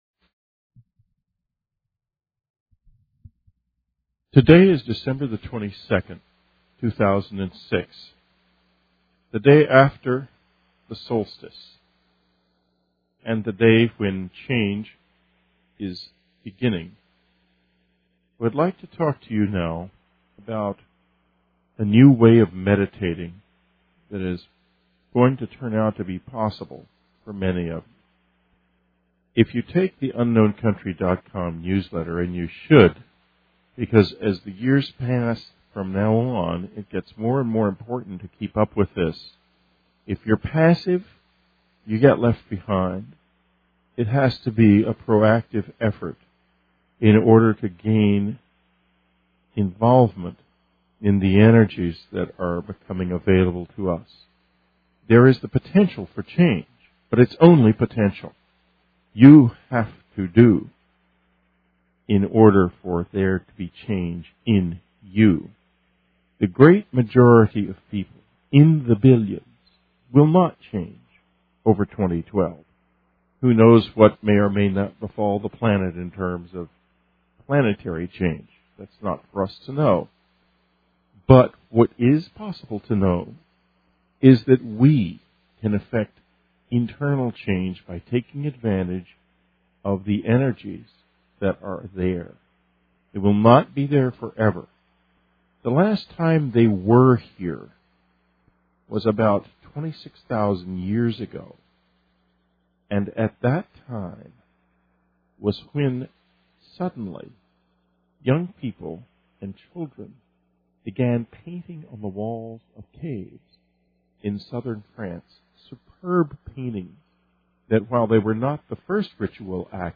Blue Pearl Meditation